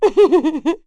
Laudia-Vox_Happy3.wav